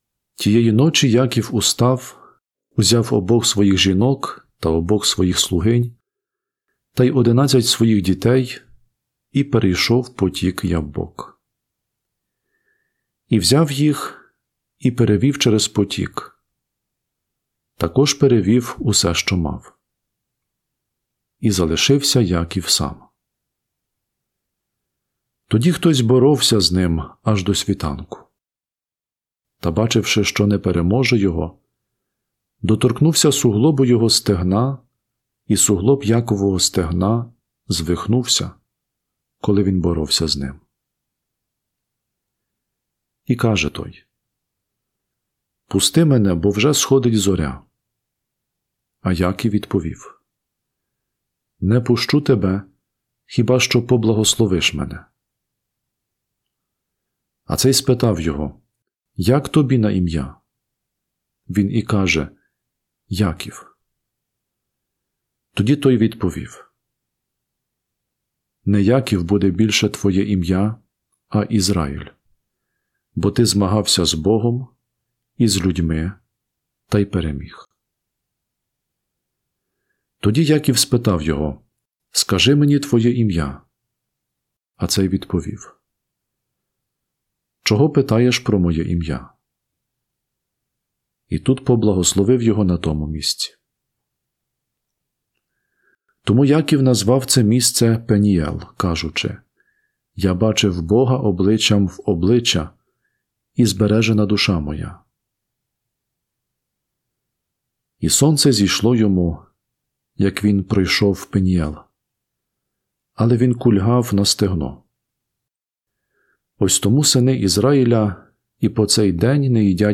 Перше читання